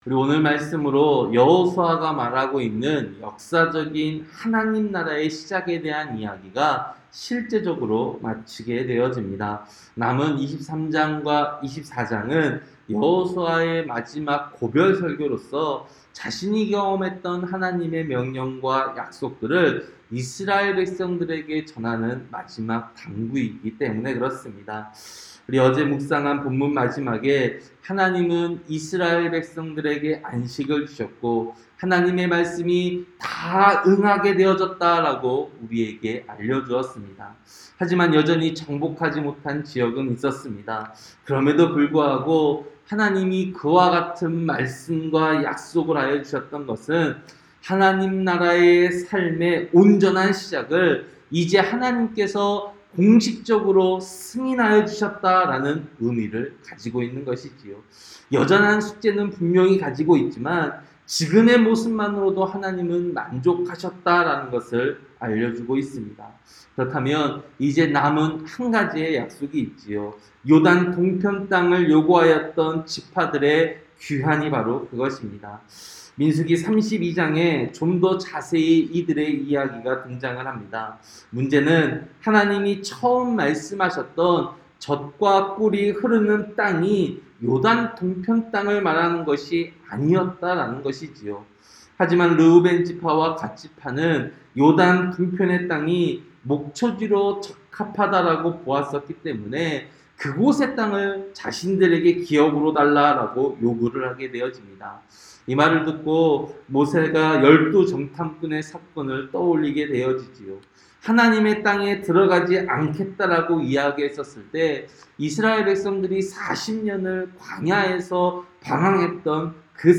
새벽설교-여호수아 22장